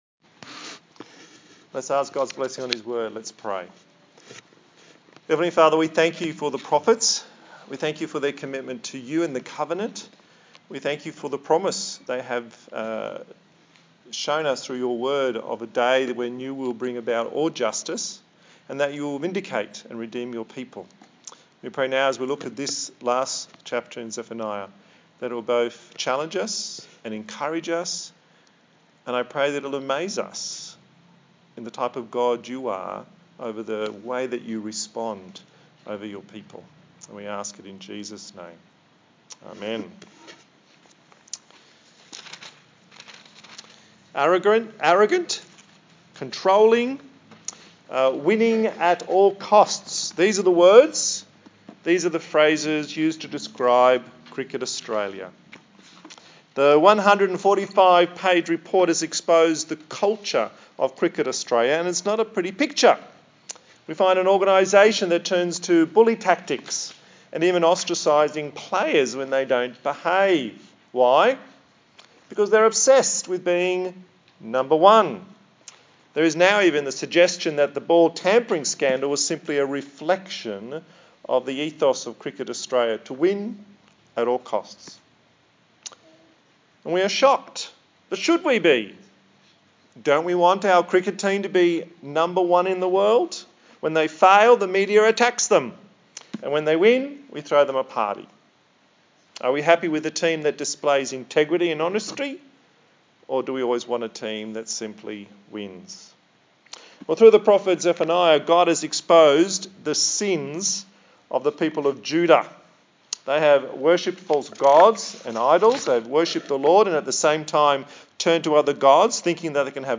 A sermon in the series on the book of Zephaniah